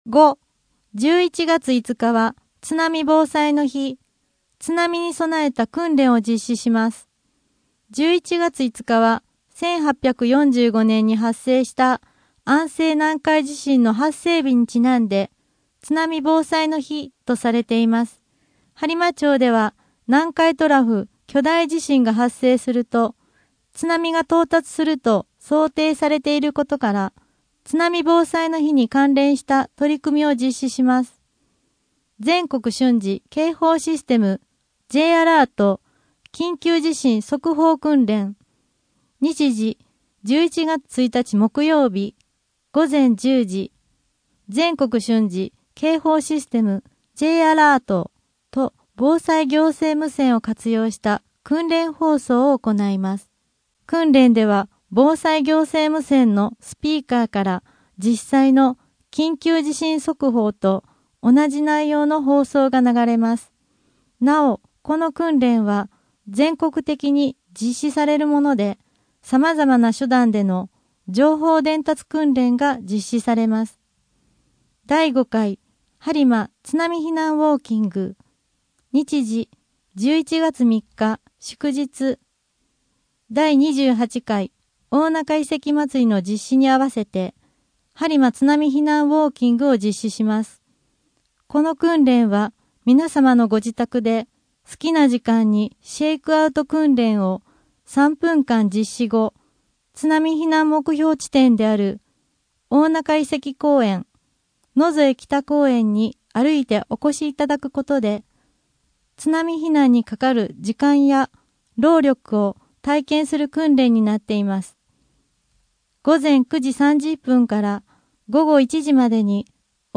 声の「広報はりま」11月号
声の「広報はりま」はボランティアグループ「のぎく」のご協力により作成されています。